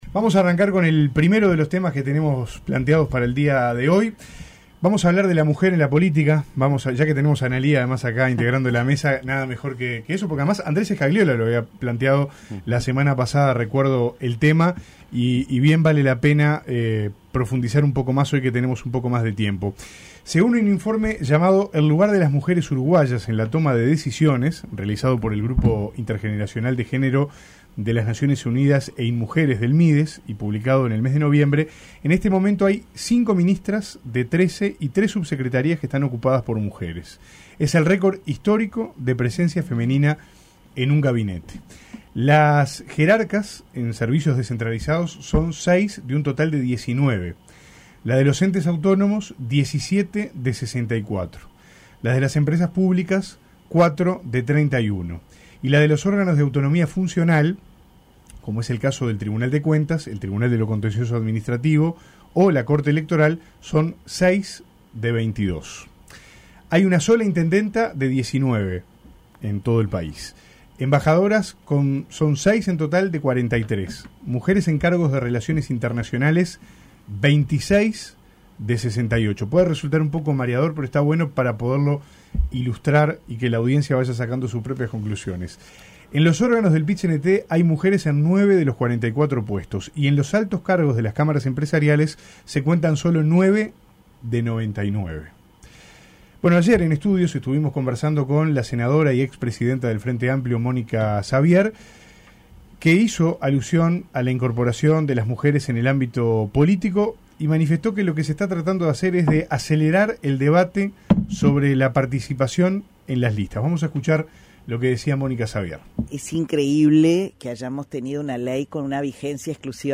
En La Tertulia de este martes, estuvieron Ana Lía Piñeyrúa, Gustavo López y Adrián Peña. Debatieron sobre la incorporación de las mujeres a la política por Ley de Cuotas.